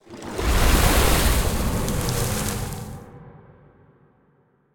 Sfx_creature_glowwhale_surfacebreath_01.ogg